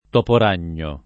toporagno [ t q por # n’n’o ]